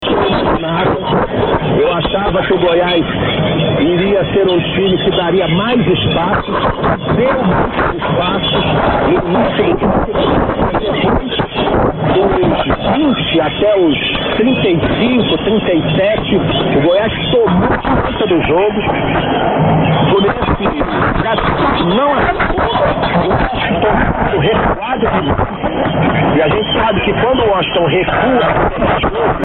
GLOBO 1100 has an ID, but something happened to the audio recorded, and it sounds like a bad bit rate recording.
100825_2300_1100_globo_(AUDIO_WENT_FUNNY_ON_CLIP).mp3